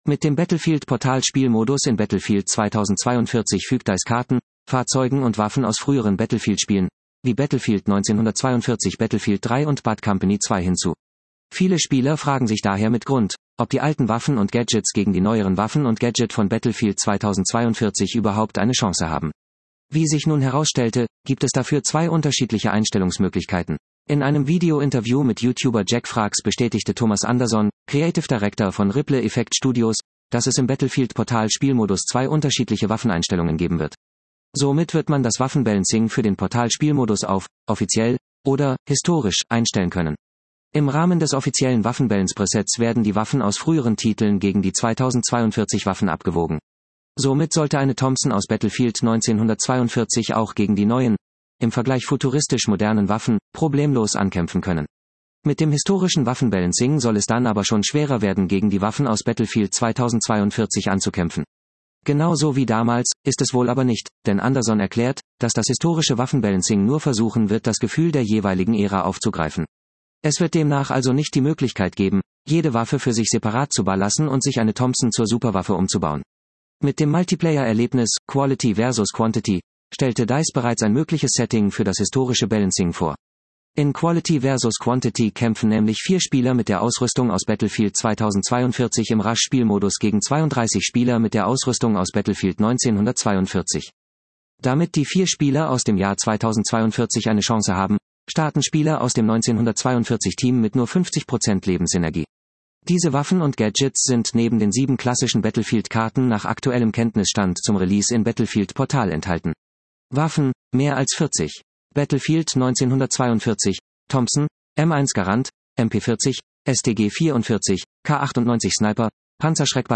Video-Interview